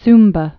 (smbə, -bä)